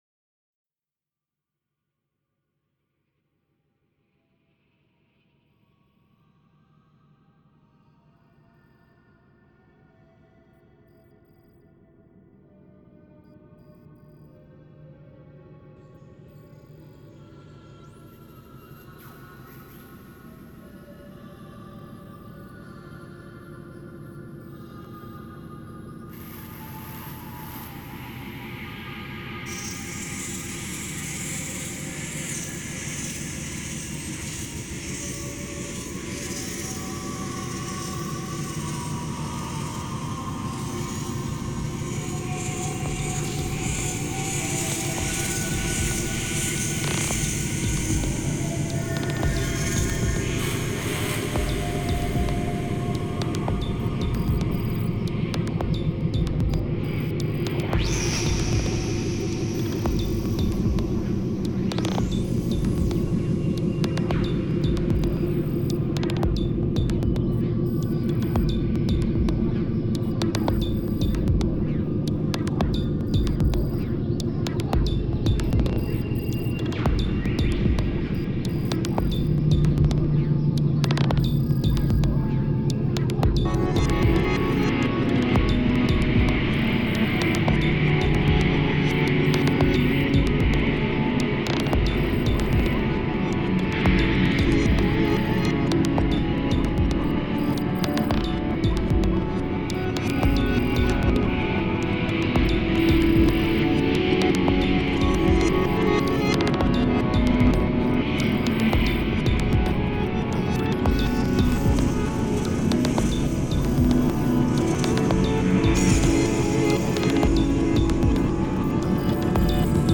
improvisational soundscapes